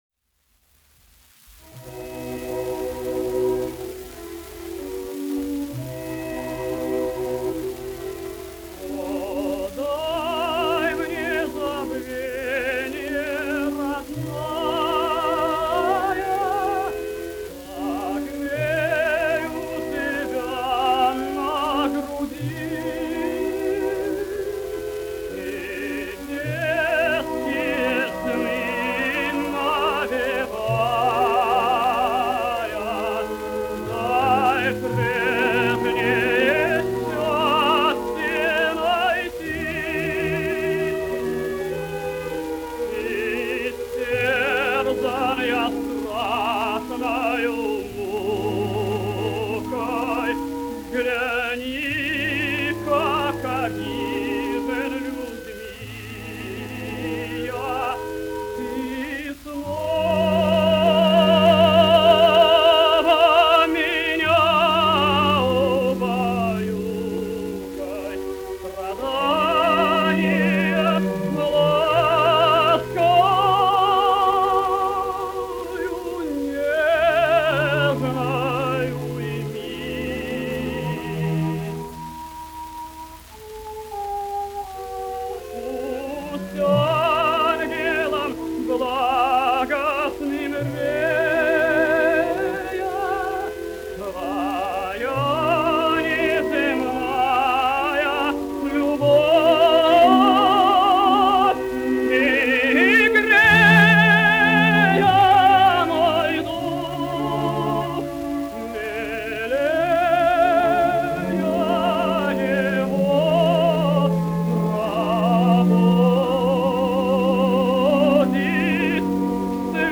Арии из опер.